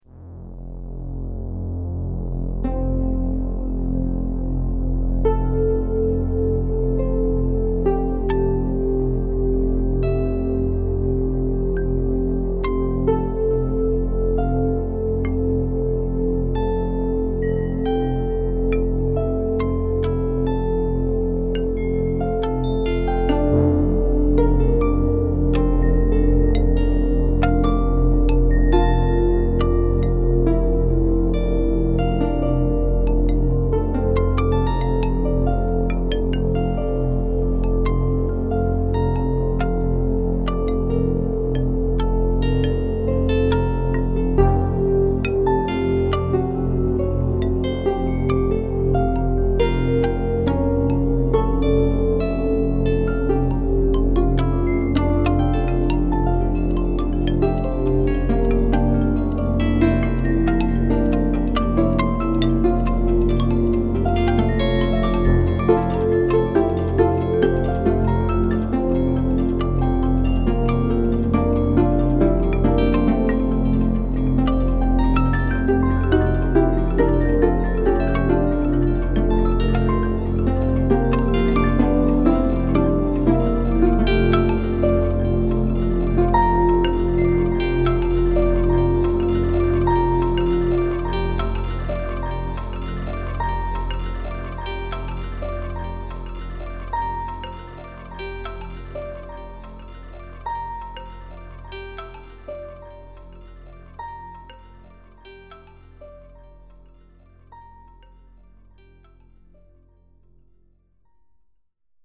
Leise rieselt der Schnee (Text & Melodie: Eduard Ebel, 1839 – 1905) Genau das ist die Idee: die Melodietöne werden als „Tonwolke” zusammengefasst und auf 5 Instrumente nach einer sich steigernden Dichteverteilung übertragen, so dass sie wie Schneeflocken „fallen”. Auf diese Weise begleitet die Melodie sich selbst über einem tiefen Grundton, der den Erdboden repräsentiert.